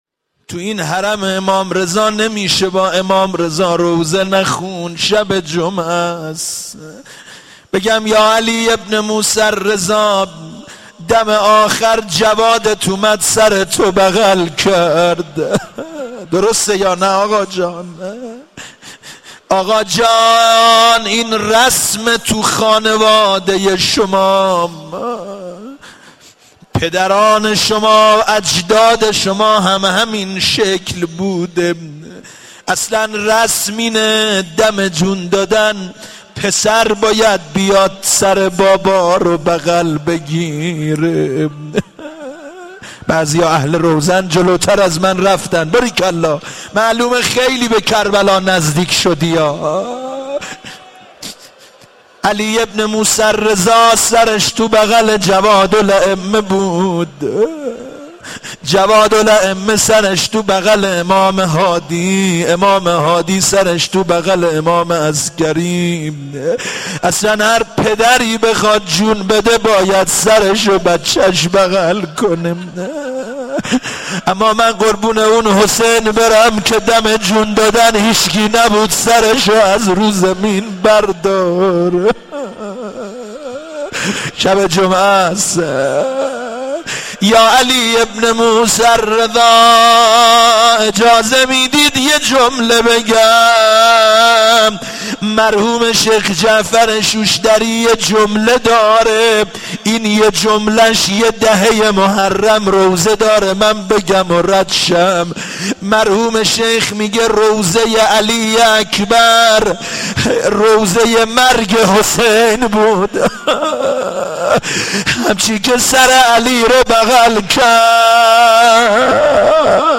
با حضور جمع زیادی از زائرین و مجاورین برگزار شد دعای کمیل